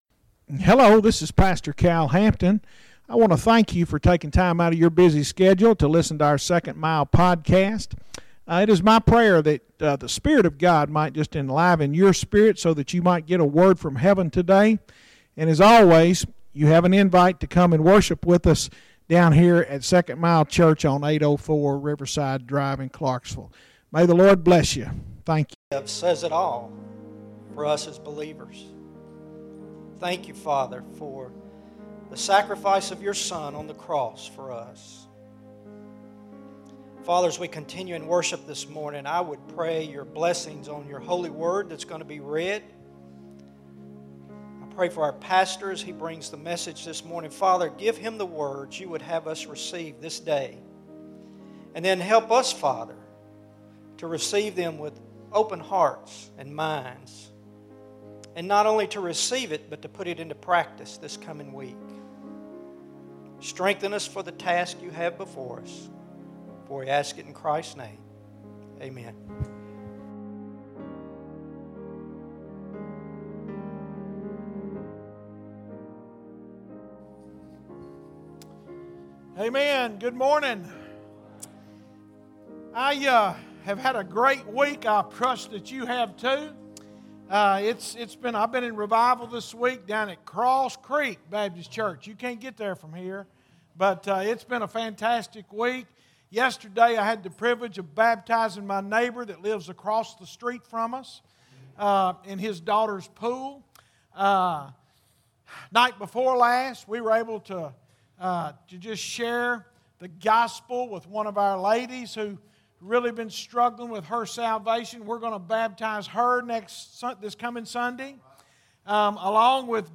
Sermons Archive - Page 131 of 311 - 2nd Mile Church